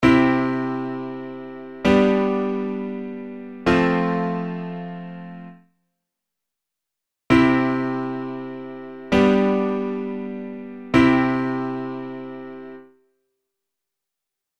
↓の音源は、前半が「T⇒D⇒SD」、後半が「T⇒D⇒T」と進んでいます。
後者の方が落ち着いているのがわかります。